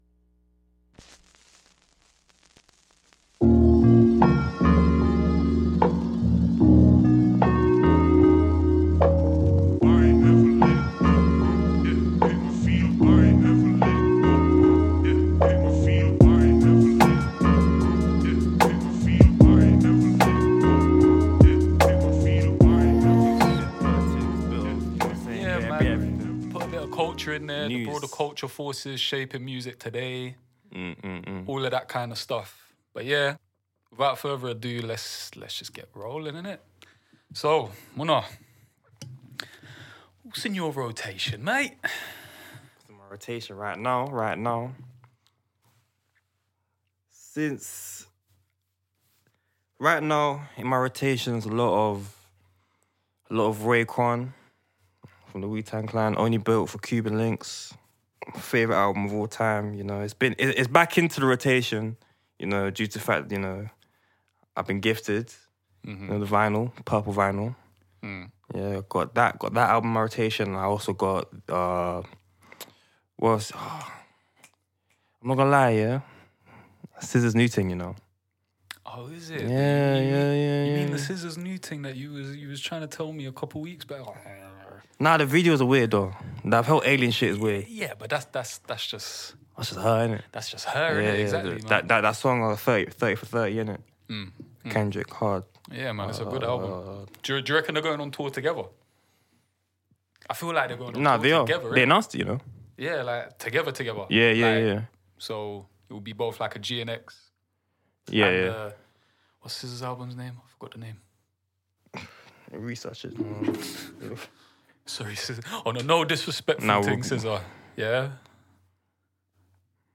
Ill 'n' Illicit is a high-energy, no-holds-barred music podcast where two passionate music heads dive deep into the intersection of hip hop, culture, and the ever-evolving world of sound. Each episode brings you fresh takes, contrasting views, and authentic discussions on everything from underground rap to mainstream hits, as well as the broader cultural forces shaping music today.
Expect plenty of humor, occasional debates, and an unapologetic take on the most talked-about trends in music.